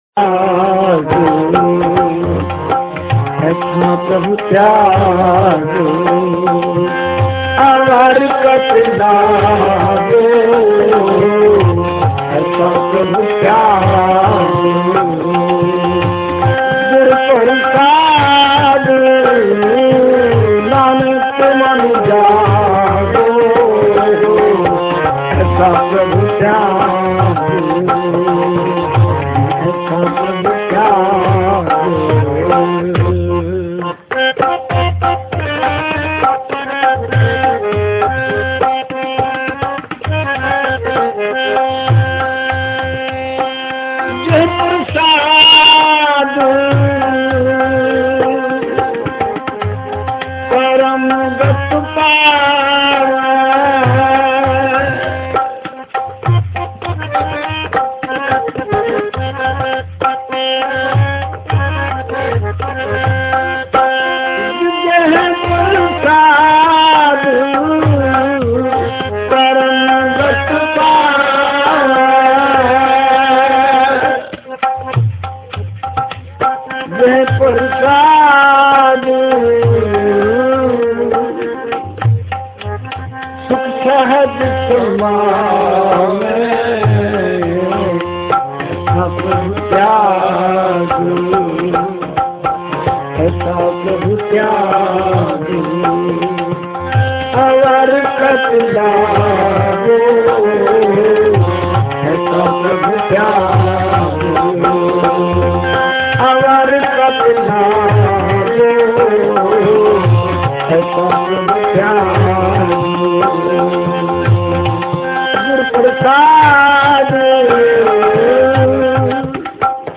The recordings below are from Shepherds Bush Gurdwara in 1999.